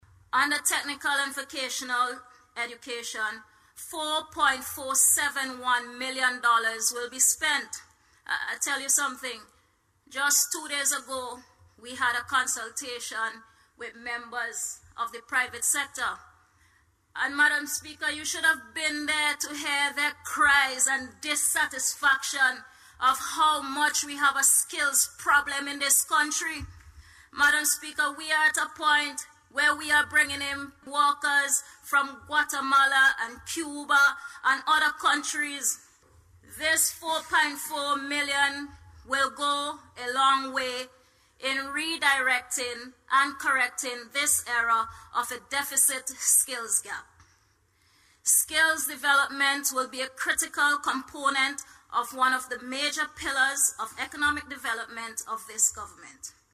This was disclosed by Minister of State in the Ministry of Education, Innovation, Digital Transformation and Information, Hon. Lavern King.